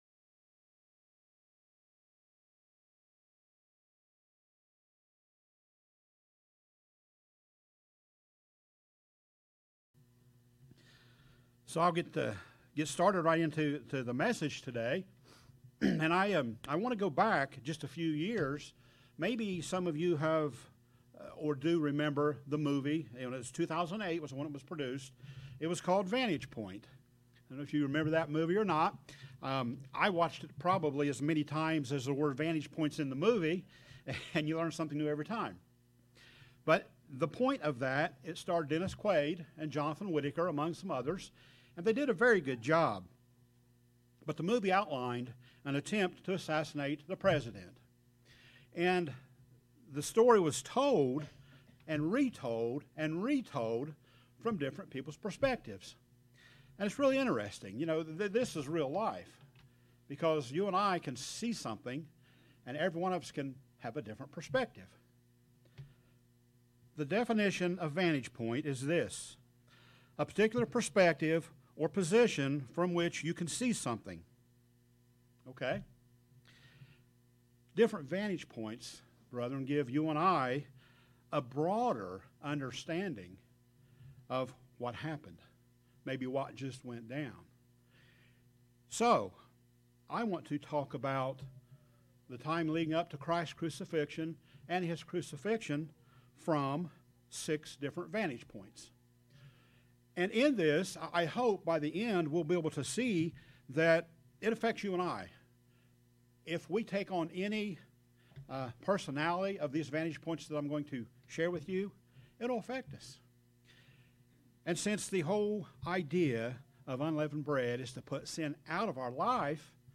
This sermon looks at the time leading up to Christ's arrest and crucifixion from 6 different vantage points. Can we see a way from these to be a better Christian?